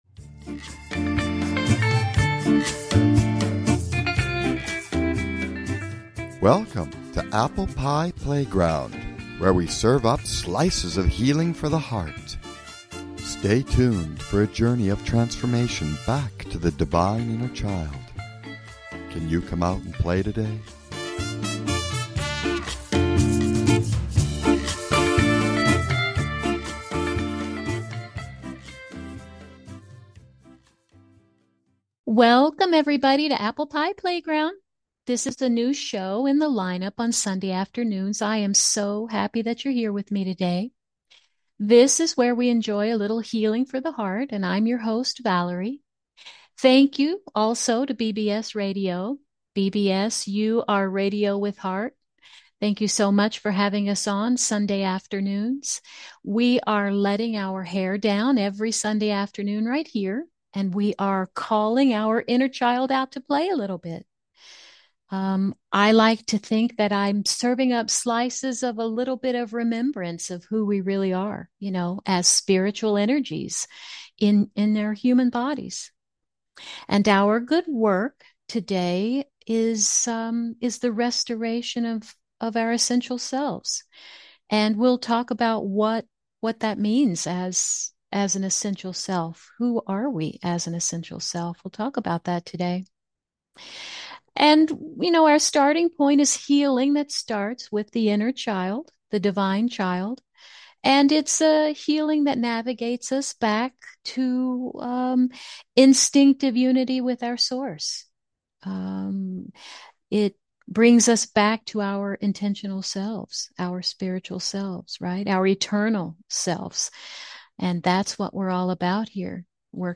Talk Show Episode, Audio Podcast, Apple Pie Playground and Spiritual Energies in Human Bodies.